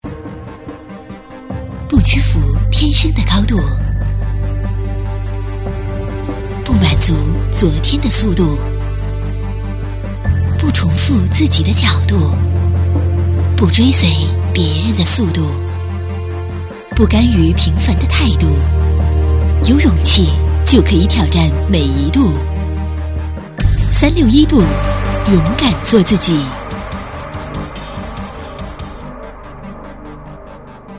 Animación por locutora china
El siguiente audio es el grabado por la locutora china de nuestros estudios, ejemplo de anuncio de animación con voz femenina.
Locutor-femenino-chino-7.mp3